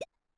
New grunk collection SFX